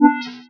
ns-signal-1.wav